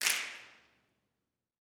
SNAPS 18.wav